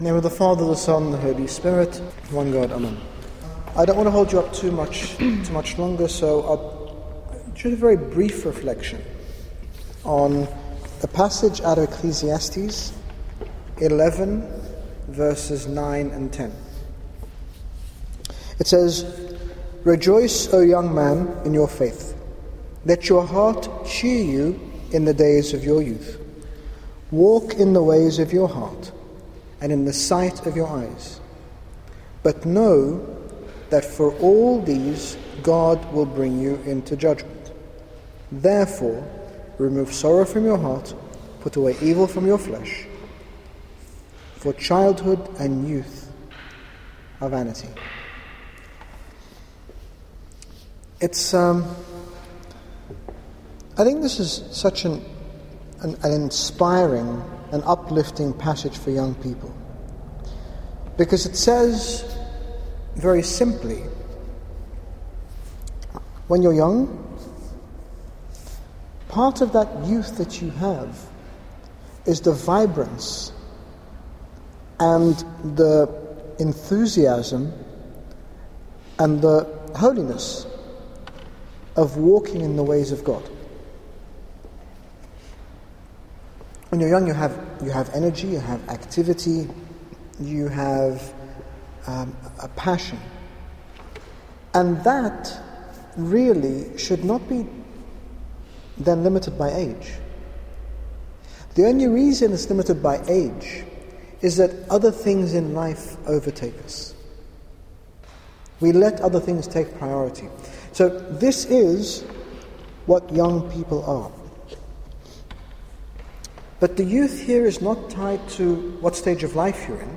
His Grace Bishop Angaelos speaks about being young, energetic and vibrant in Christ regardless of age, circumstance, or any other factor. His Grace speaks of our Lord as the source of energy and dynamism.